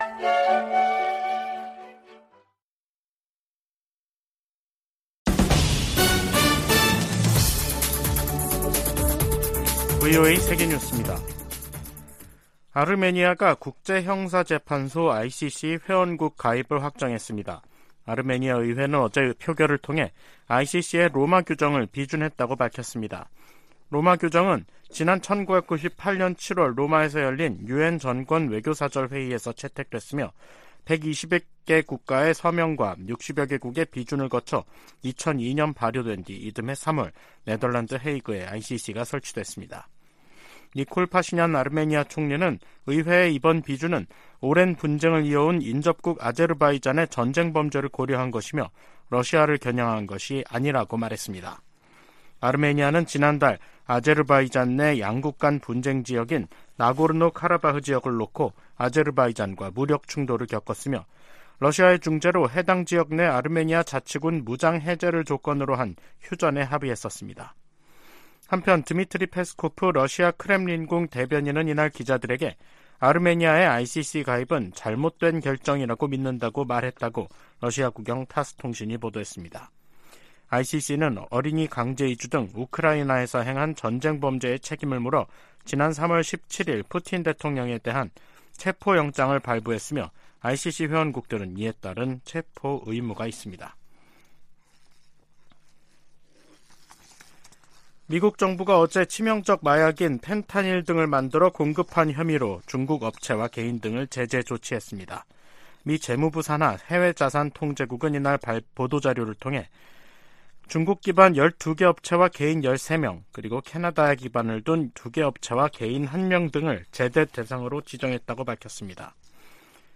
VOA 한국어 간판 뉴스 프로그램 '뉴스 투데이', 2023년 10월 4일 2부 방송입니다. 북한 국방성은 미국의 '2023 대량살상무기(WMD) 대응 전략'을 "또 하나의 엄중한 군사정치적 도발"이라고 규정했습니다. 미 국무부는 핵 보유가 주권 행사라는 최선희 북한 외무상의 주장에, 북한은 절대 핵보유국으로 인정받지 못할 것이라고 강조했습니다. 미 국방부는 북한과 러시아 간 추가 무기 거래가 이뤄져도 놀랍지 않다는 입장을 밝혔습니다.